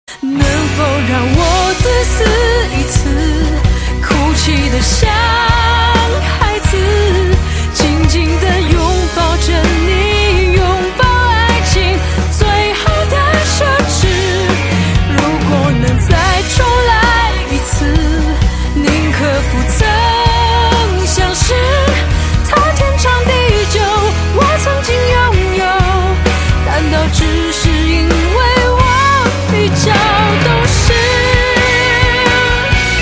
M4R铃声, MP3铃声, 华语歌曲 120 首发日期：2018-05-15 07:04 星期二